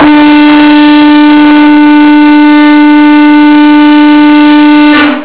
霧笛がうるさいです。スピーカーの前で録音してみました。